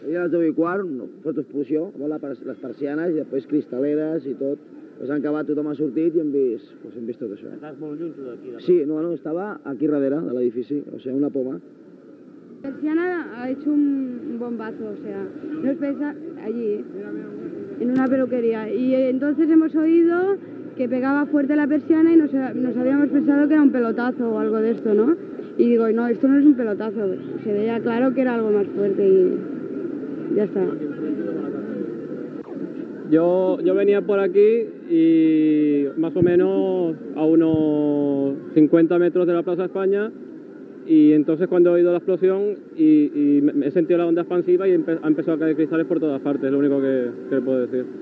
Declaracions de diversos testimonis de l'atemptat d'ETA a la Plaça Espanya de Barcelona
Informatiu